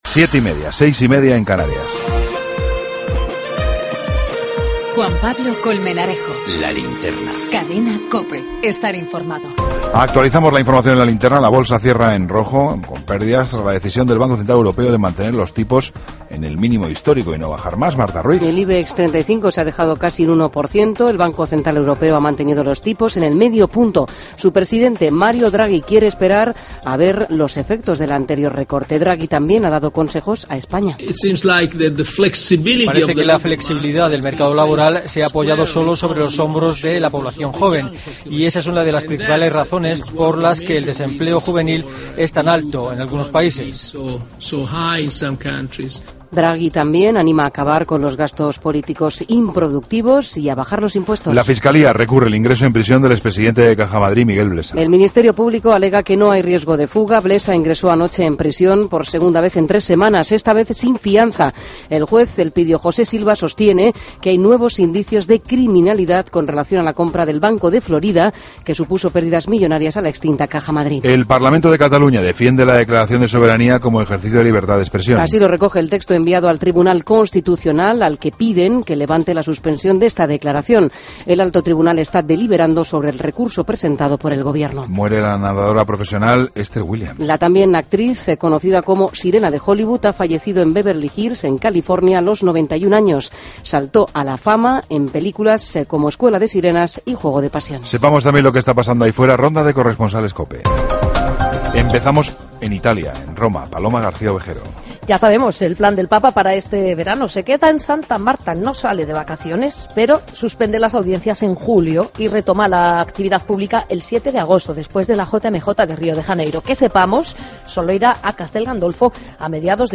Ronda de corresponsales.